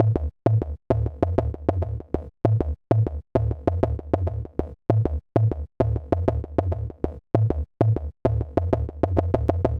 Rock Star - Jungle Bass.wav